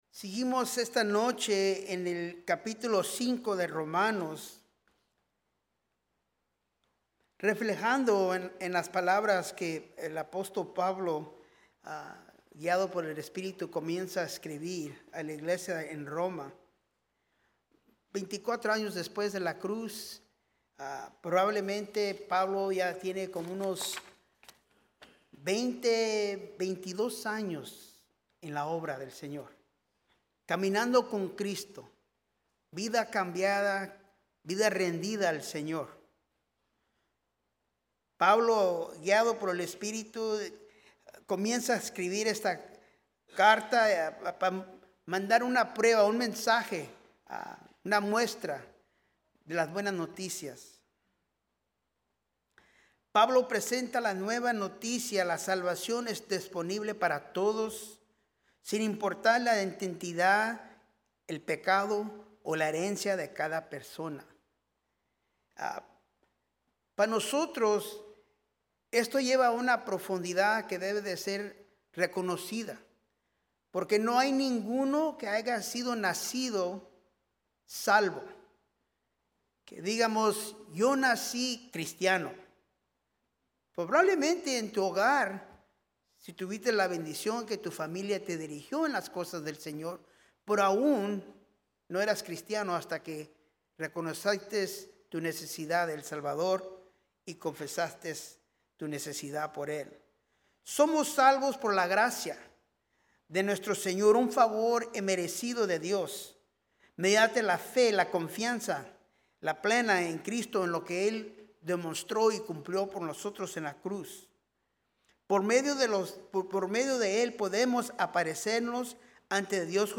Un mensaje de la serie "Invitado Especial."